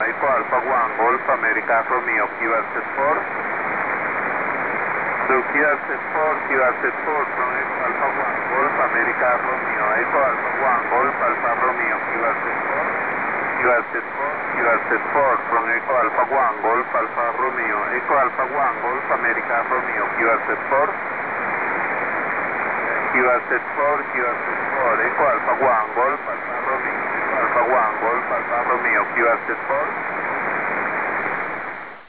Here are some sound clips of signals received summer 2010 on 70mhz.